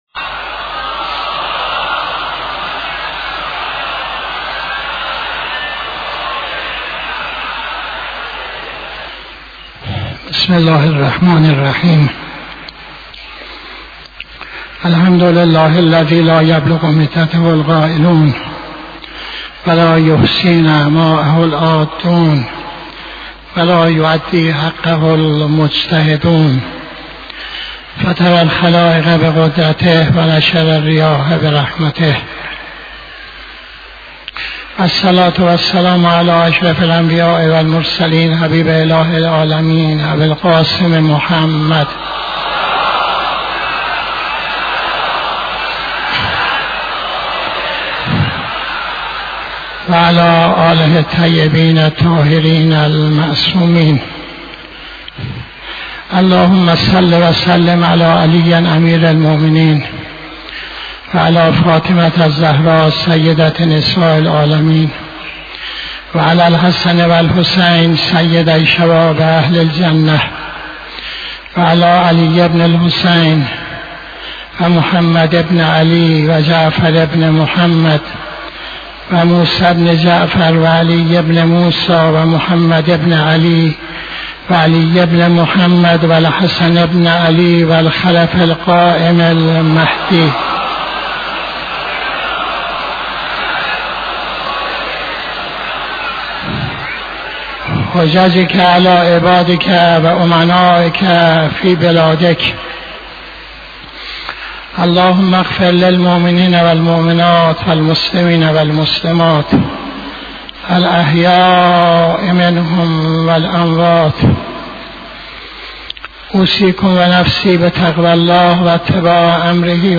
خطبه دوم نماز جمعه 01-02-85